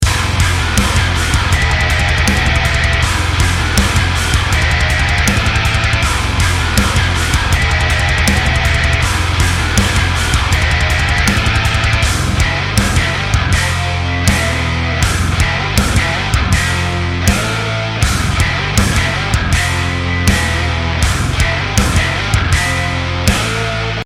Submission Audio Djinn Bass是一款专为金属音乐而设计的MIDI低音虚拟乐器。它能够提供极致的清晰度和低音区域的冲击力，适合极端、前卫或现代的音乐风格。
- 使用Dingwall ng3作为采样源，这是一款旗舰级的现代低音吉他。
- 拥有多种效果器，包括Darkglass B7K Ultra和Vintage Ultra，Neural DSP Fortin Nameless Suite和Archetype Plini等，可以调整低音的饱和度、失真、压缩、均衡等参数。